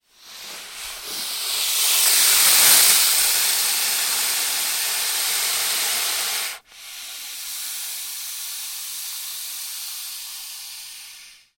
На этой странице собраны реалистичные аудиозаписи: от угрожающего шипения до плавного скольжения по поверхности.
Звук шипения крупного тигрового питона